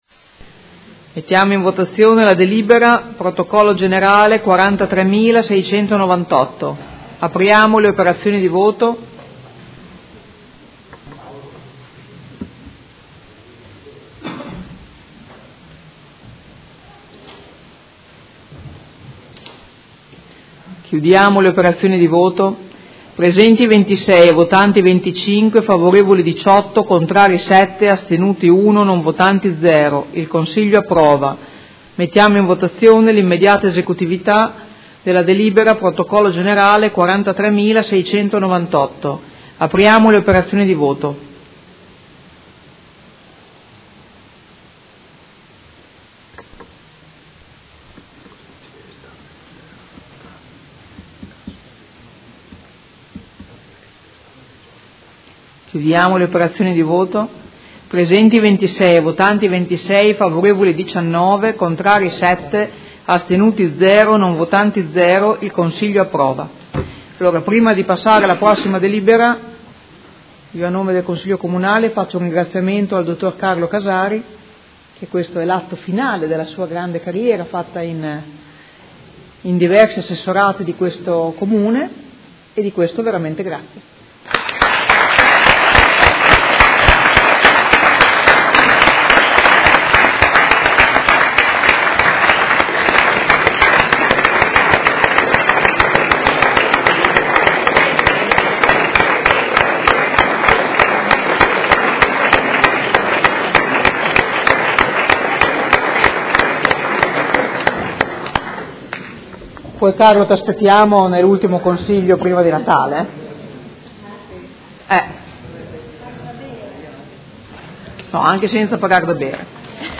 Seduta del 27/04/2017 Mette ai voti.
Presidentessa